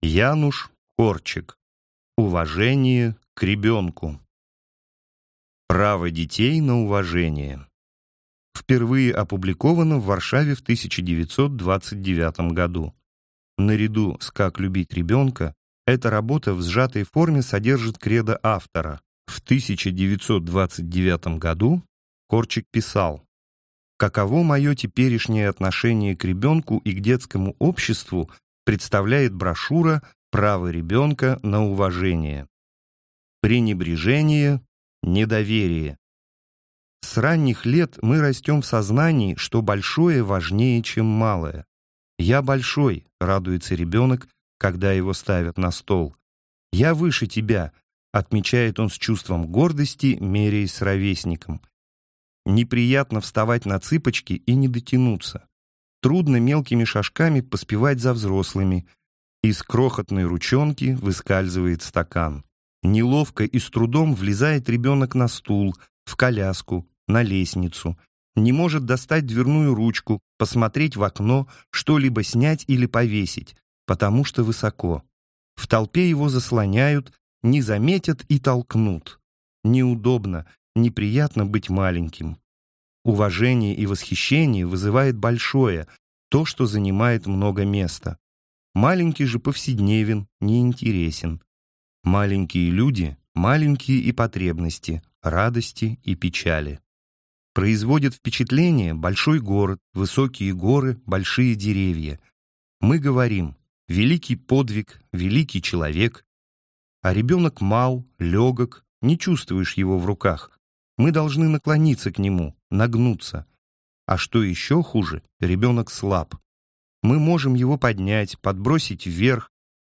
Аудиокнига Уважение к ребенку | Библиотека аудиокниг